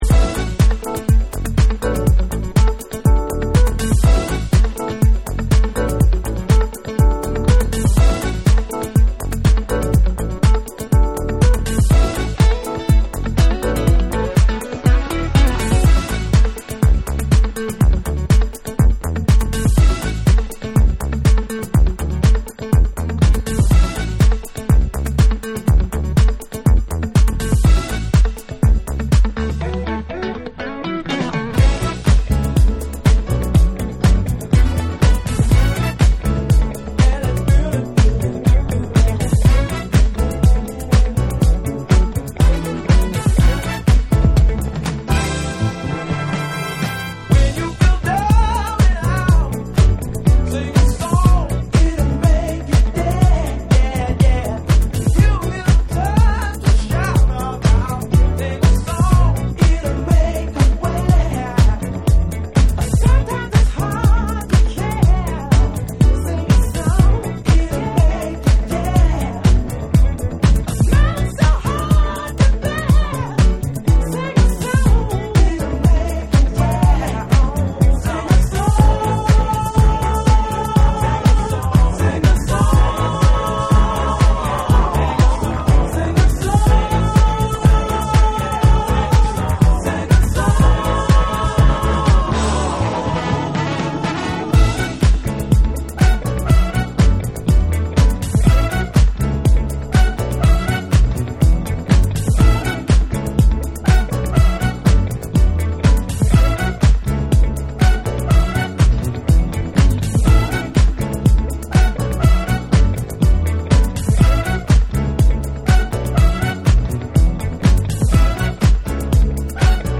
DANCE CLASSICS / DISCO / RE-EDIT / MASH UP